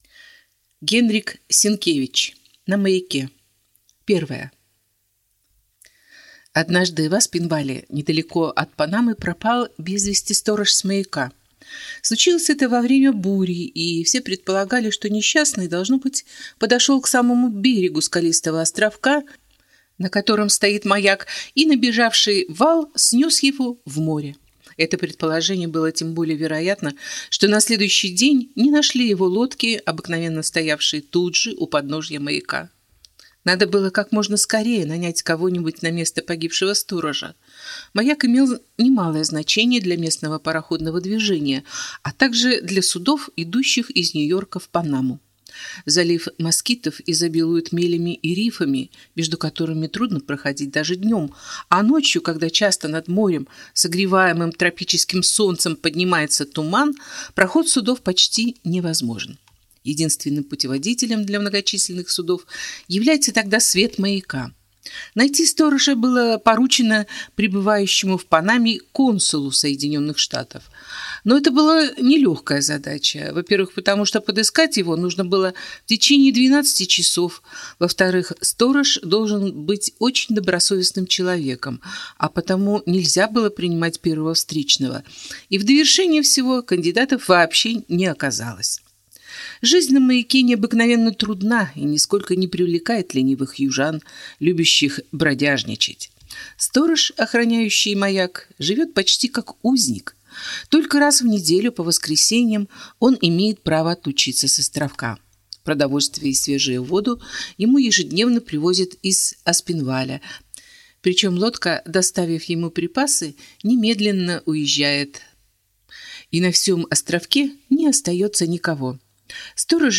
Аудиокнига На маяке | Библиотека аудиокниг